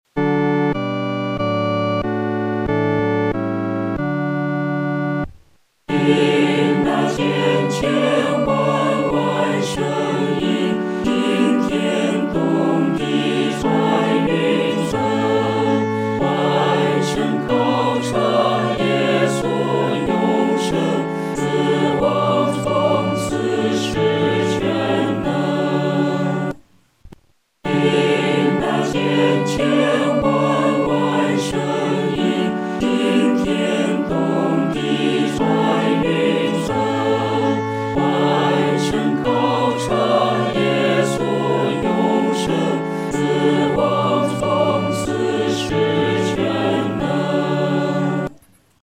四声合唱